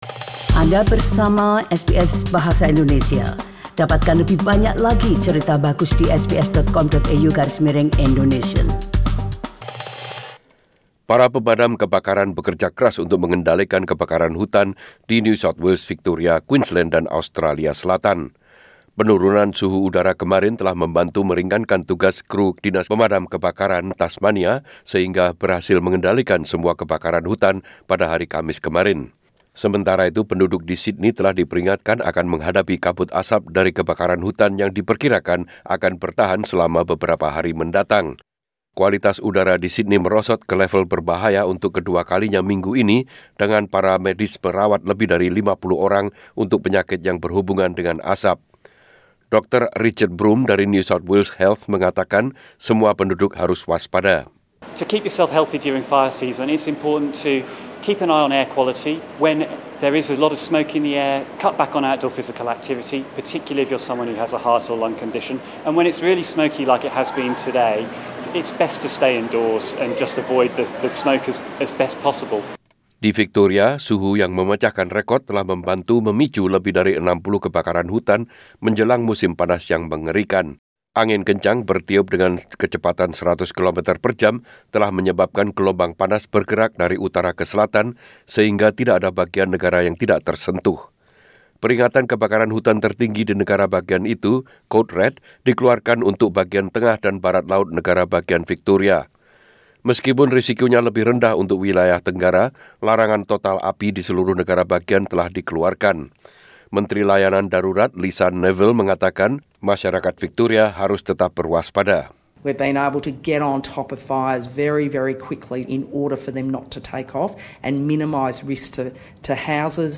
SBS Radio News in Indonesian - 22 November 2019